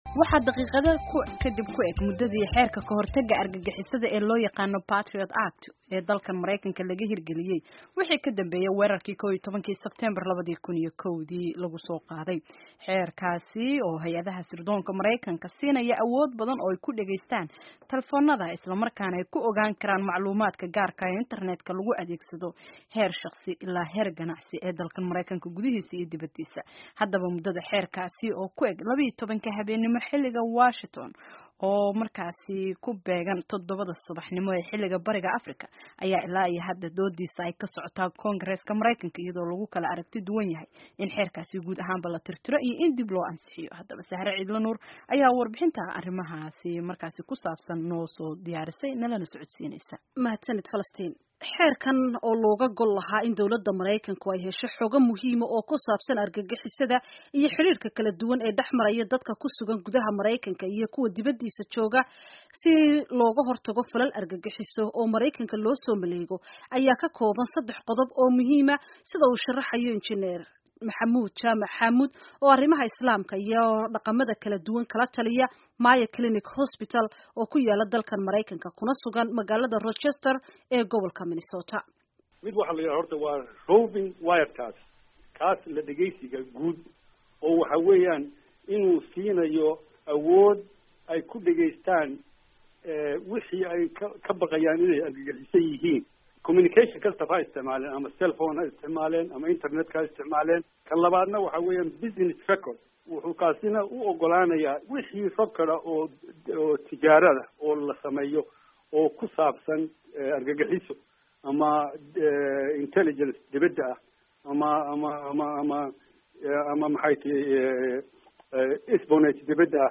warbixintan